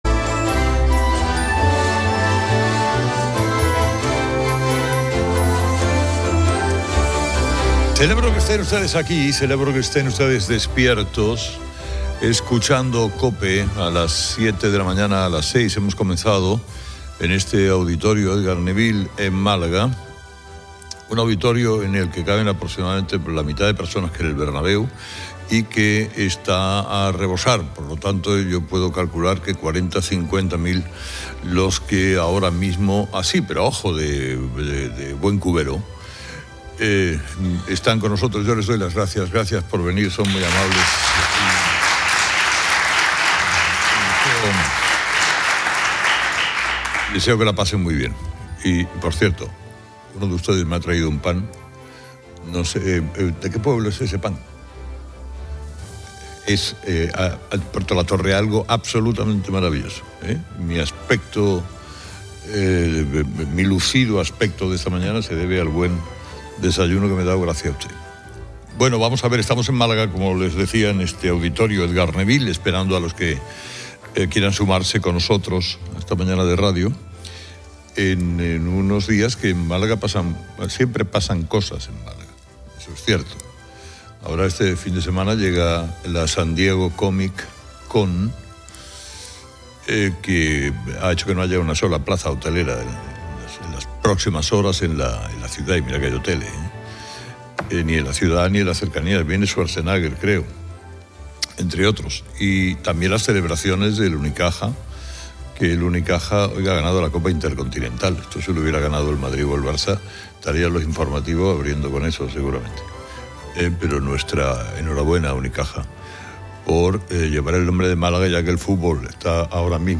COPE retransmite desde el Auditorio Edgar Neville en Málaga, donde se destaca la gran asistencia.